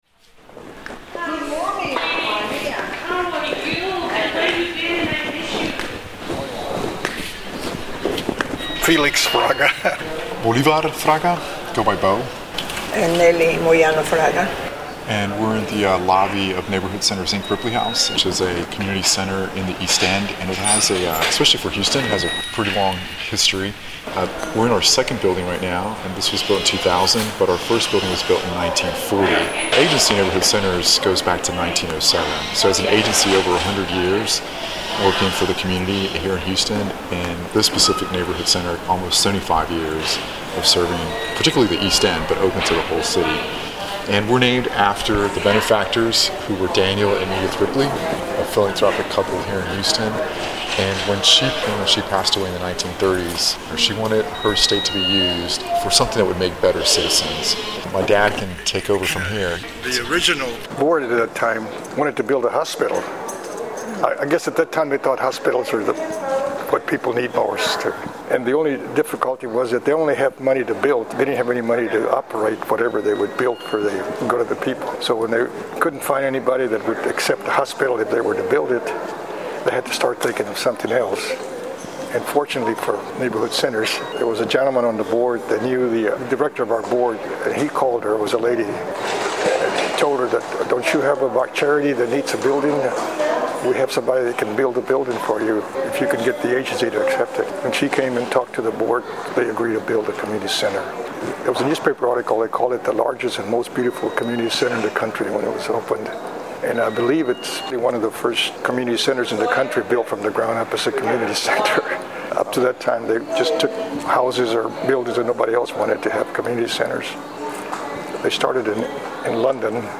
lead a tour of Neighborhood Centers Inc. Ripley House